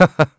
Riheet-Vox-Laugh_kr-02.wav